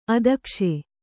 ♪ adakṣe